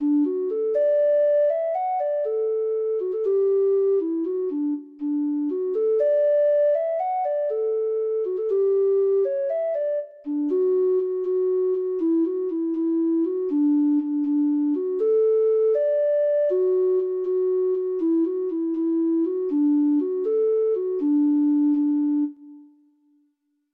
Traditional Trad. I Leave You To Guess (Irish Folk Song) (Ireland) Treble Clef Instrument version
Traditional Music of unknown author.
Irish